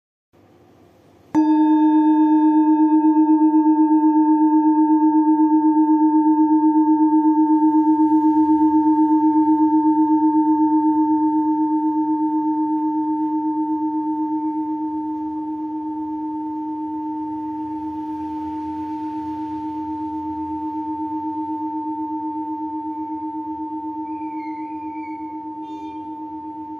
Buddhist Hand Beaten Jambati Singing Bowl, with Triangle Design, Carving, Select Accessories
Material Bronze